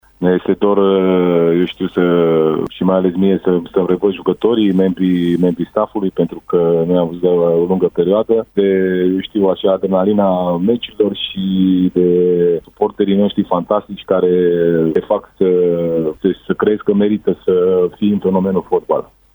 Cei doi tehnicieni, invitați azi la Arena Radio, au vorbit și despre stările de spirit ale loturilor pe care le conduc de la distanță: